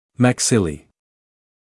[mæk’sɪliː][мэк’силиː]челюсти (верхняя и нижняя)